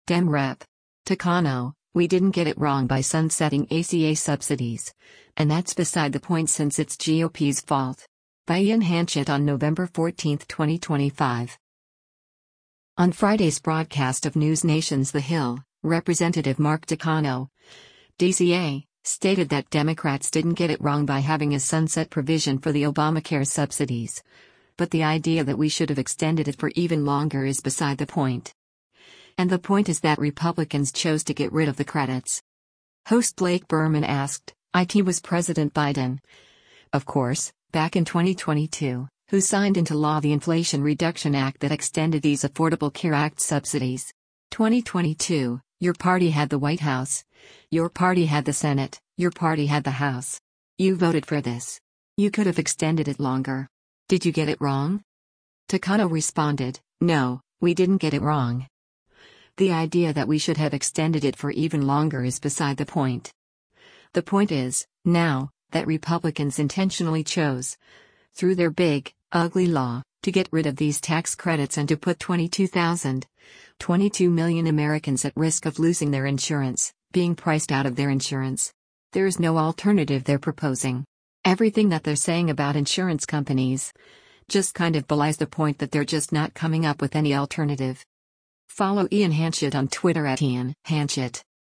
On Friday’s broadcast of NewsNation’s “The Hill,” Rep. Mark Takano (D-CA) stated that Democrats “didn’t get it wrong” by having a sunset provision for the Obamacare subsidies, but “The idea that we should have extended it for even longer is beside the point.” And the point is that Republicans chose to get rid of the credits.